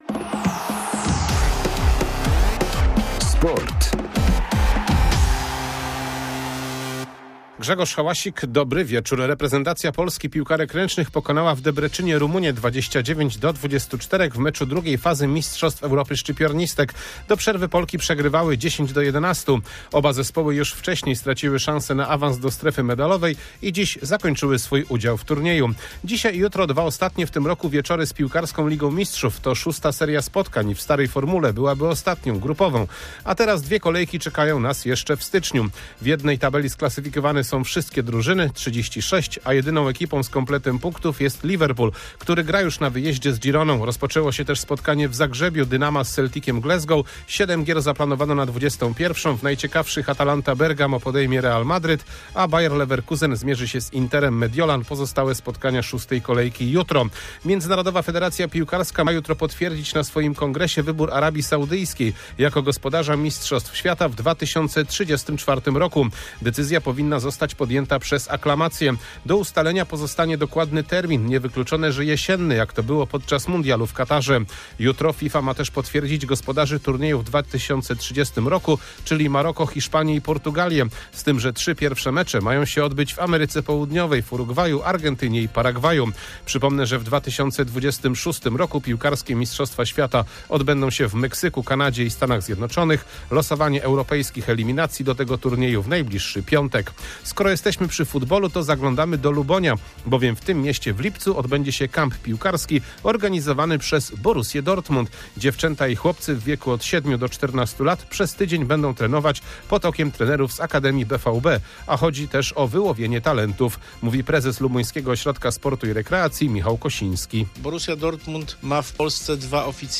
10.12.2024 SERWIS SPORTOWY GODZ. 19:05